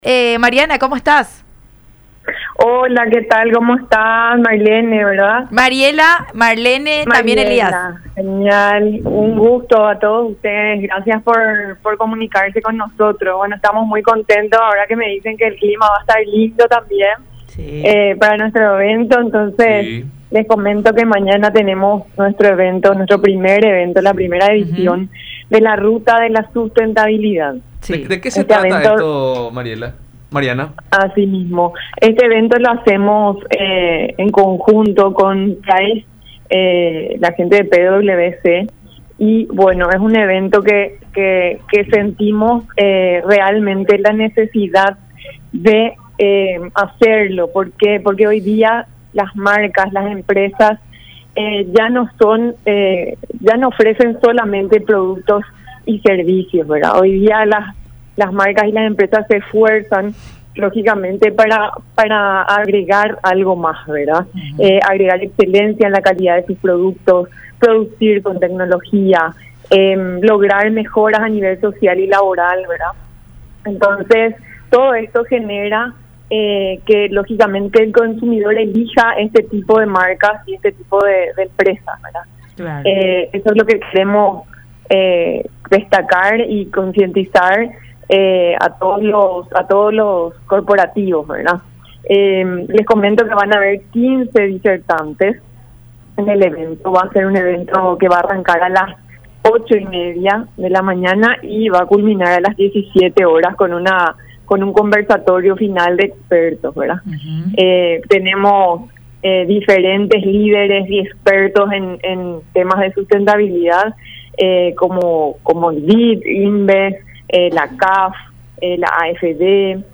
en diálogo con La Unión Hace La Fuerza por Unión TV y radio La Unión.